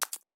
s001_Click.wav